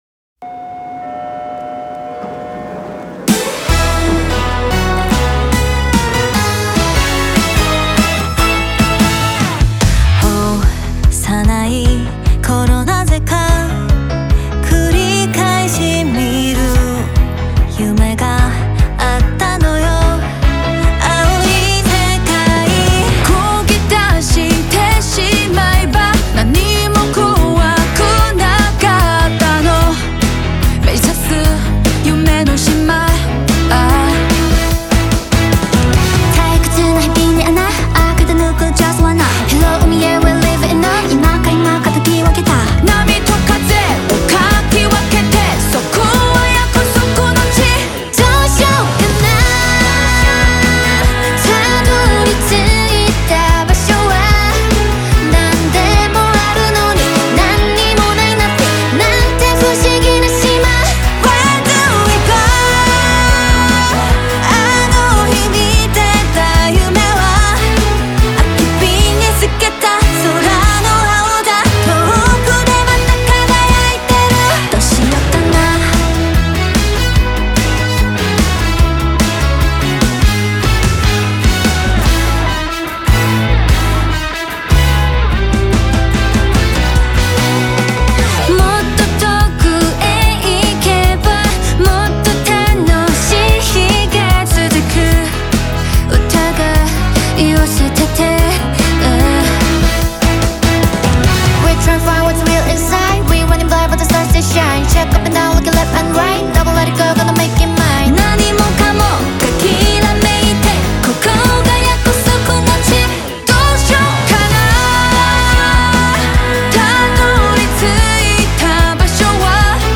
Качество: 320 kbps, stereo
K-pop